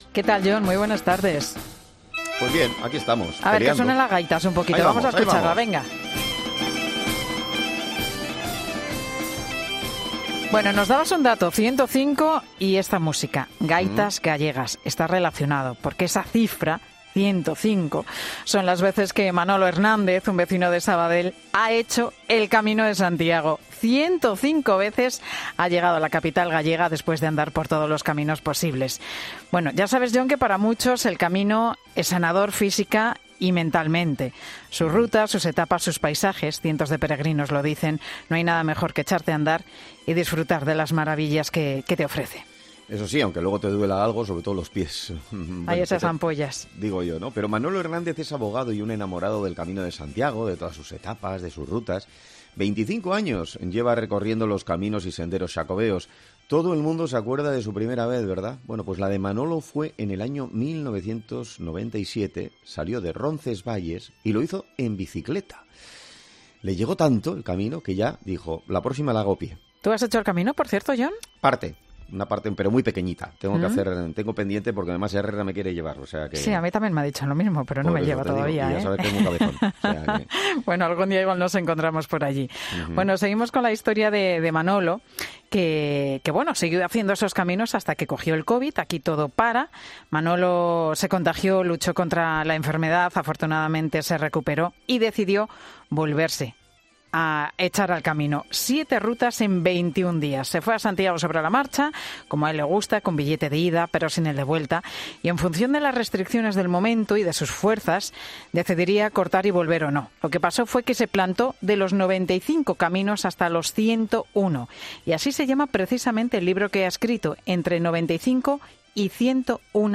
En Herrera en COPE, hemos hablado con él